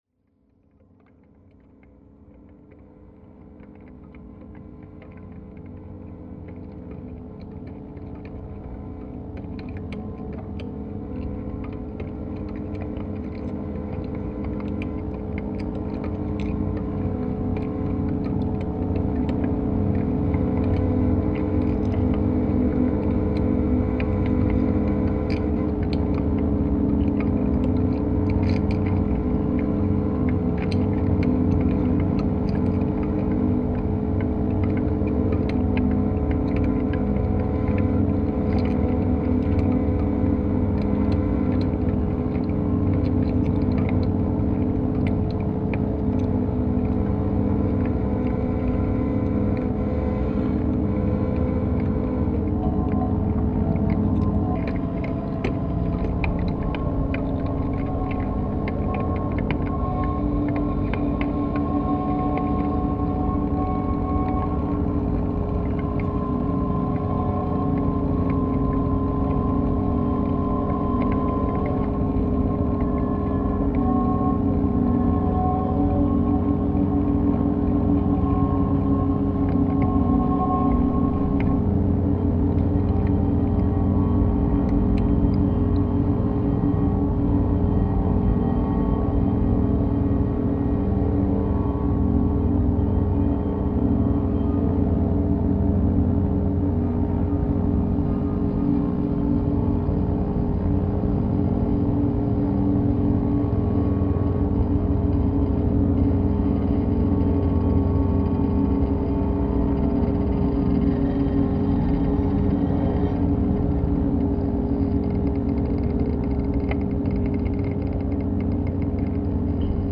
laptop, live electronics, real-time sampling, Max-Msp